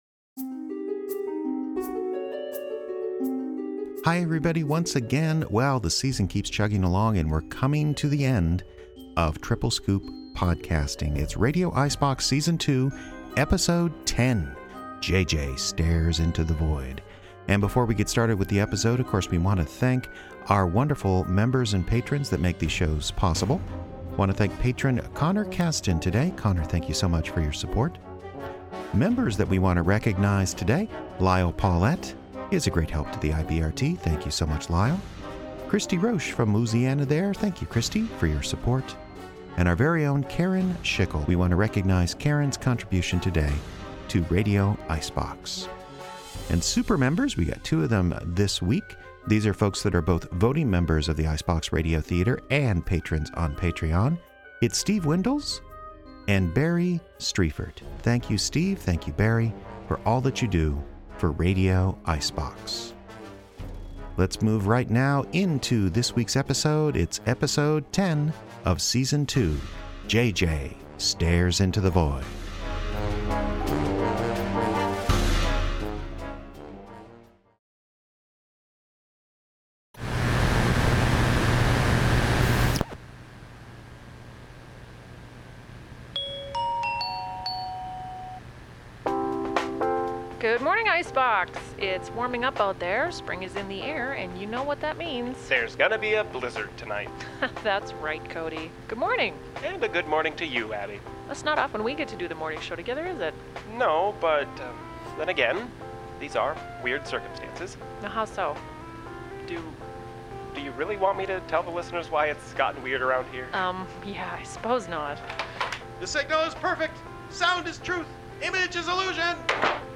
Audio Drama